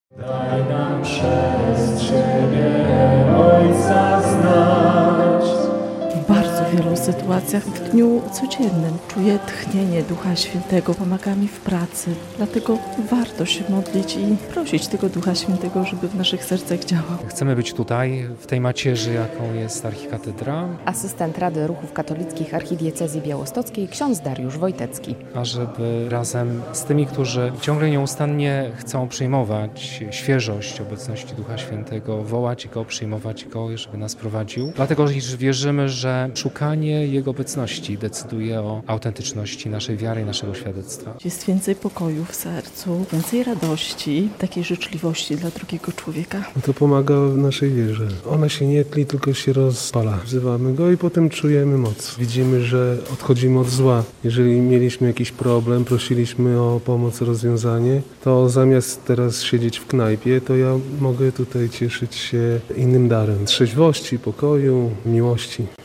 Obchody święta Zesłania Ducha Świętego - relacja
Z tej okazji w sobotni (7.06) wieczór, w wigilię tej uroczystości, w białostockiej katedrze spotkali się członkowie wspólnot kościelnych z całej archidiecezji, by modlić się o dary Ducha Świętego.